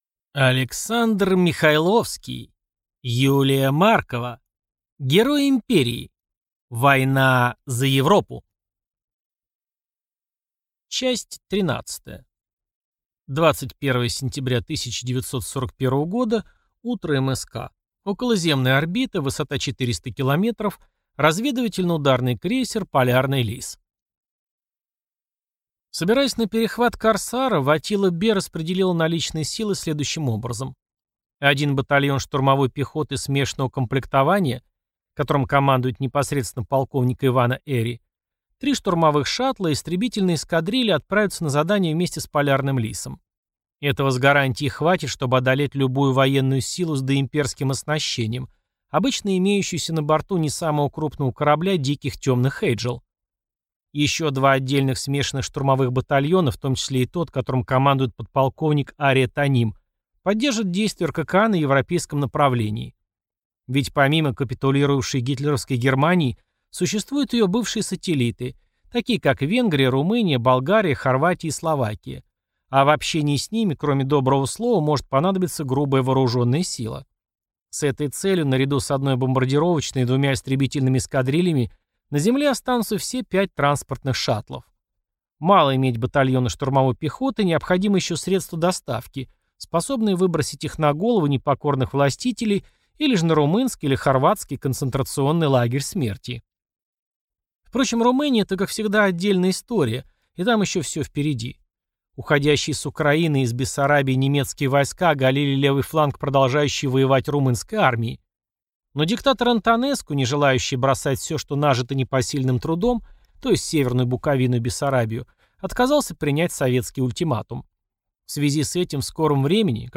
Аудиокнига Герой империи. Война за Европу | Библиотека аудиокниг